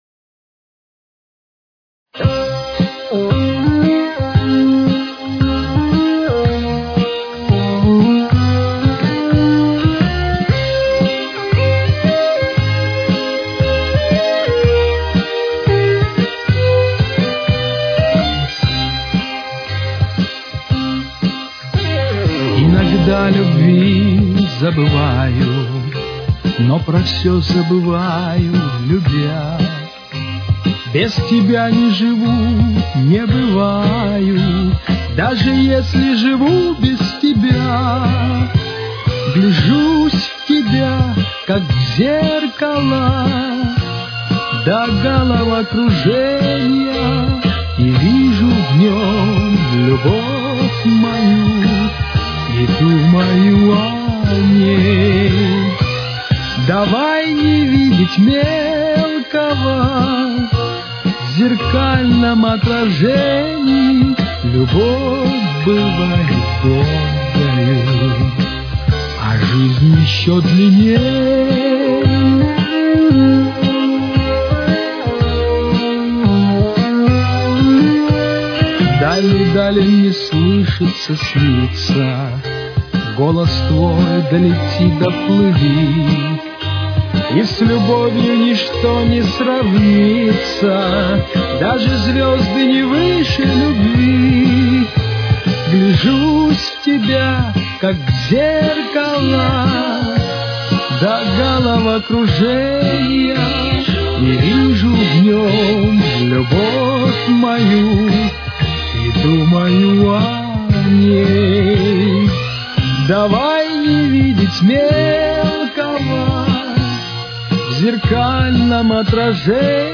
Темп: 117.